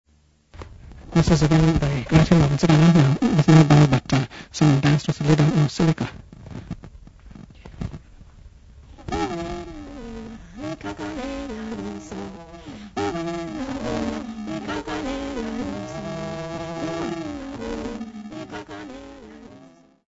Xhosa ladies
Folk music
Sacred music
Field recordings
Ntsikana great hymn with dance performance intermittent with discussion on the song
96000Hz 24Bit Stereo